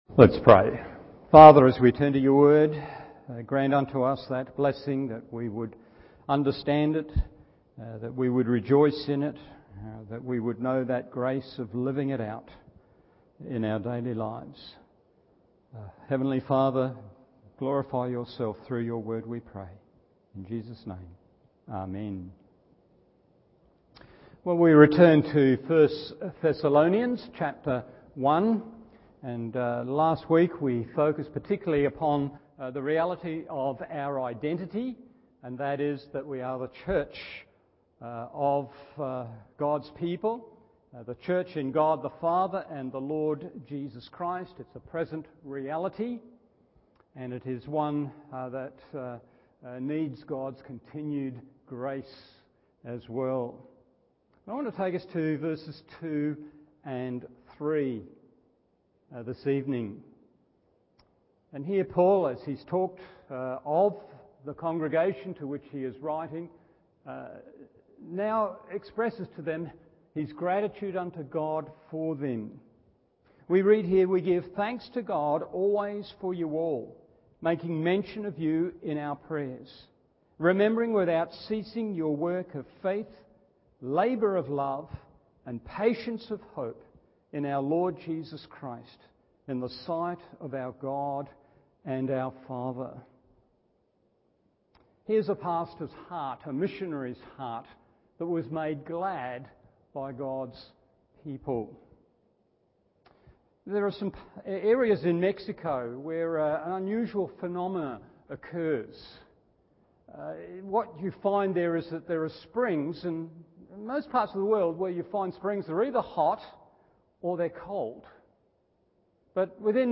Evening Service 1 Thessalonians 1. They Work 2. The Really Work 3. The Really…